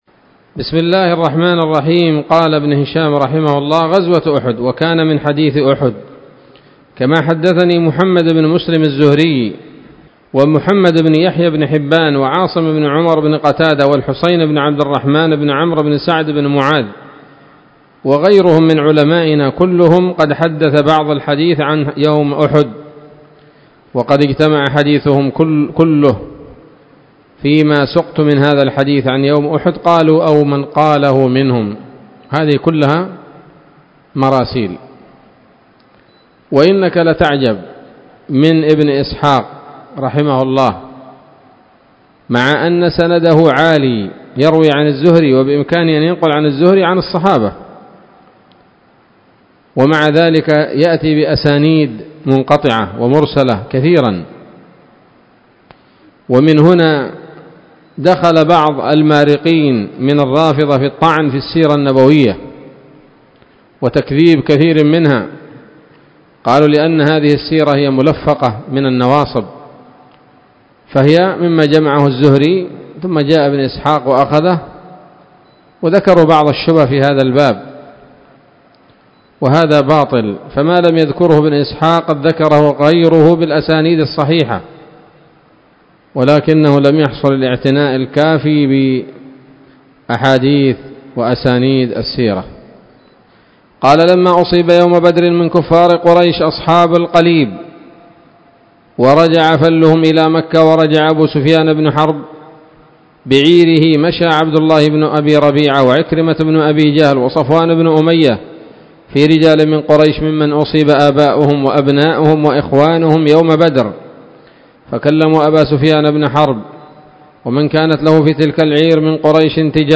الدرس الثالث والخمسون بعد المائة من التعليق على كتاب السيرة النبوية لابن هشام